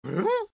AV_horse_question.ogg